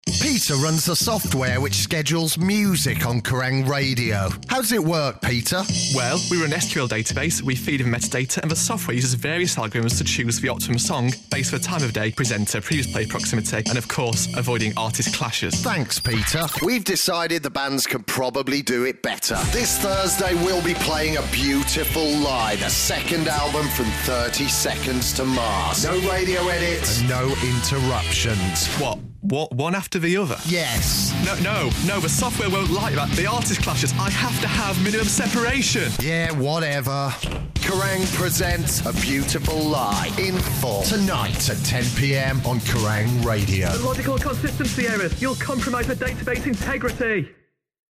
Our on air promo for the brand new show Kerrang! Presents... In Full! Every Thursday at 10pm, we play our favourite rock albums in full.